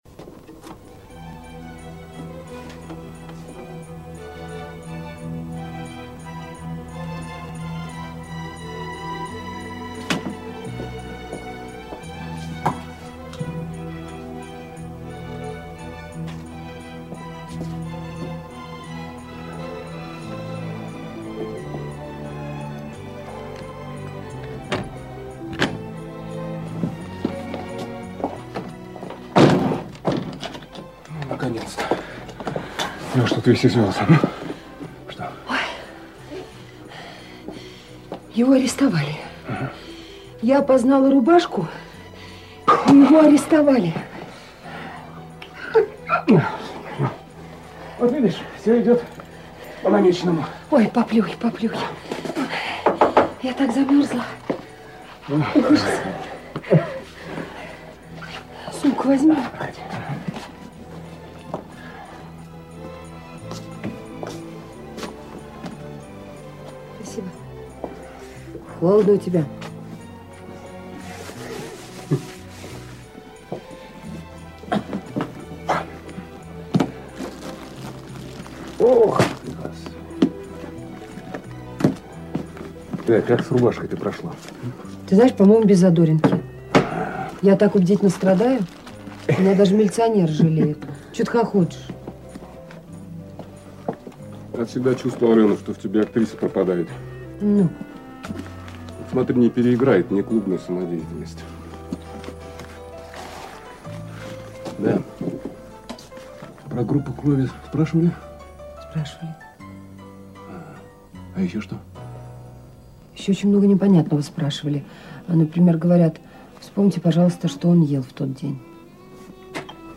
наверное вторую пьесу(с 3 минуты ) исполняет этот же оркестр?